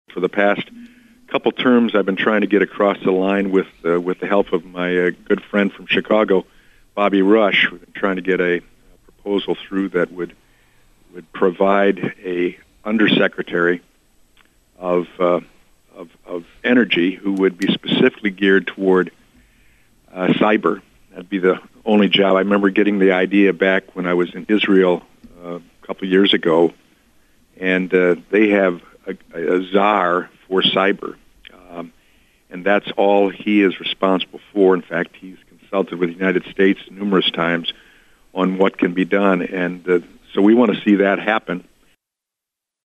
Adrian, MI – United States Congressman Tim Walberg was on a recent 7:40am break, and talked about the recent cyber attack on the Colonial Pipeline… and brought up Line 5 in Michigan as well.